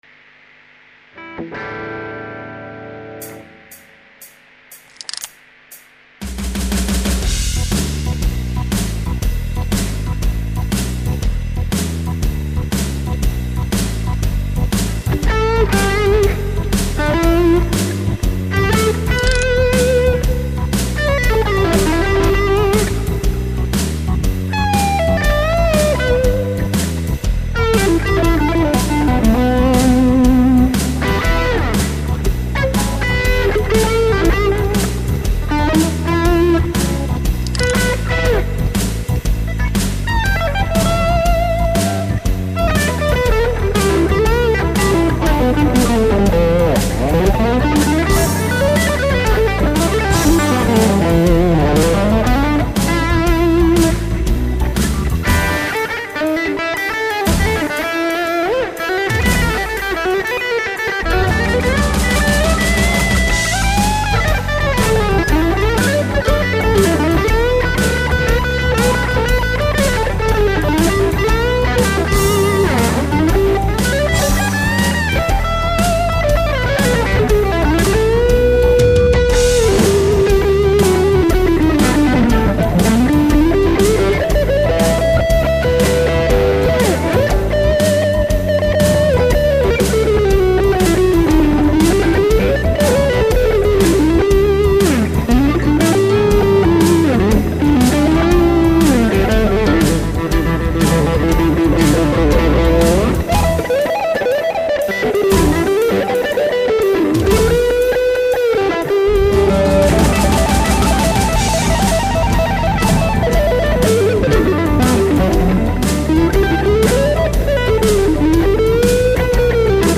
Solos sur backing tracks.
heavy powerful slow blues (1 prise ) 2004 [ hmm... pas si slow, en fait.
slow_blues.mp3